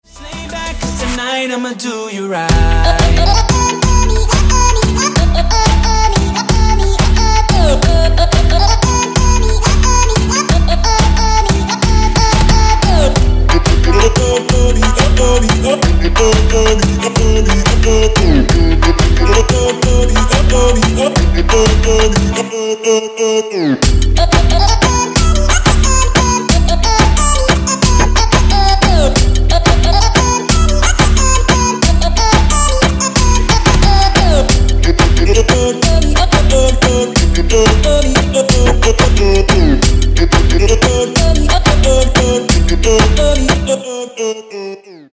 • Качество: 160, Stereo
Electronic
Trap
club